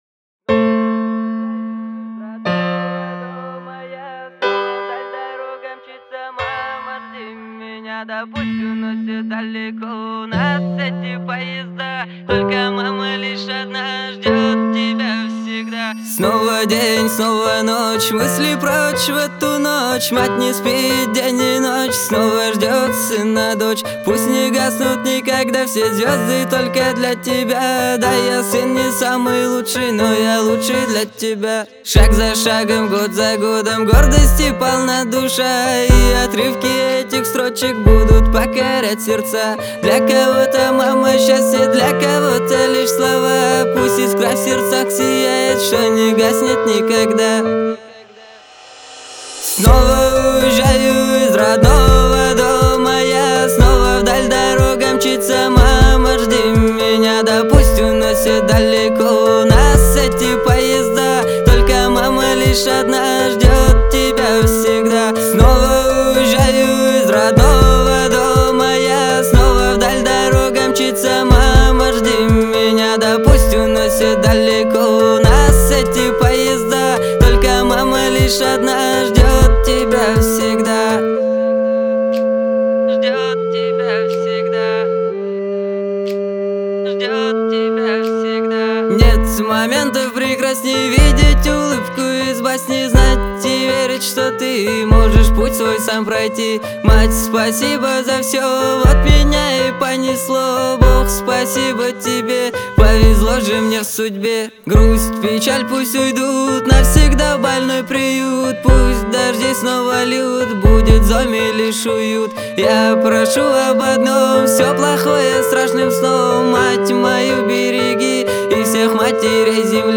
😭 такая песня грустная •́ ‿ ,•̀